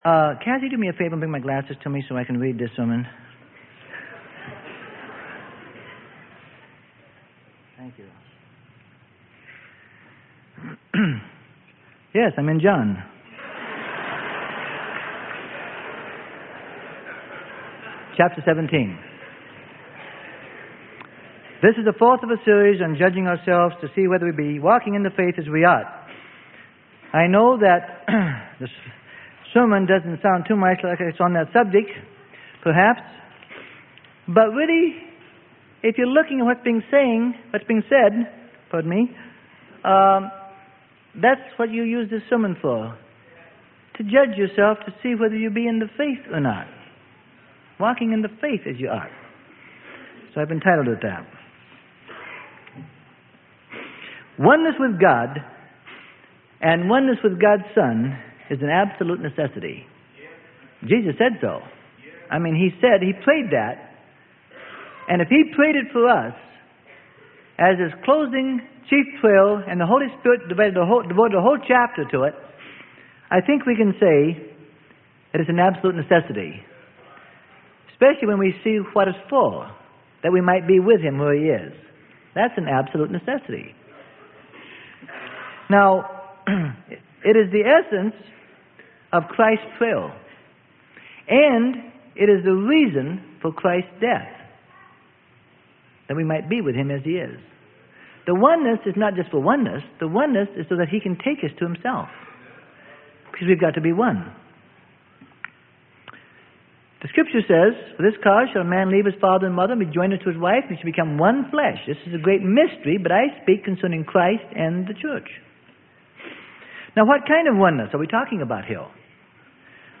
Sermon: JUDGING OURSELVES TO SEE WHETHER WE BE WALKING IN THE FAITH AS WE OUGHT.